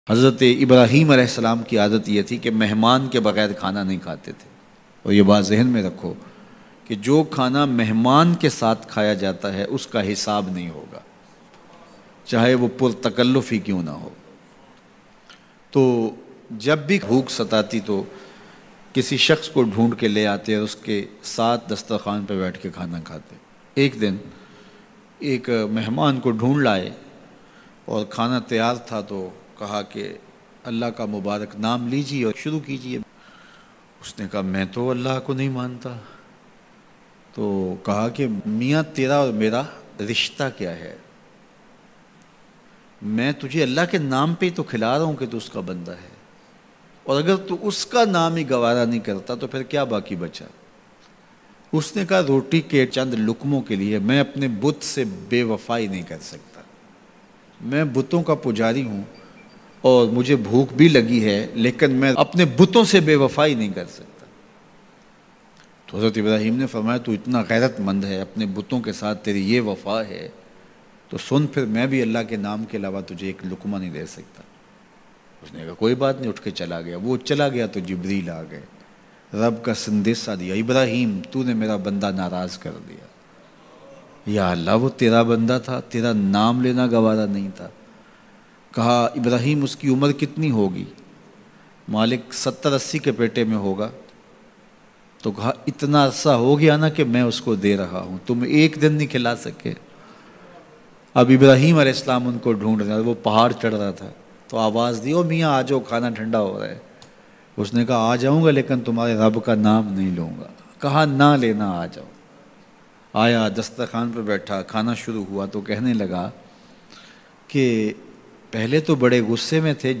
RUSOOL-E-KHUDA Ne Butt Prast ki shart Bayan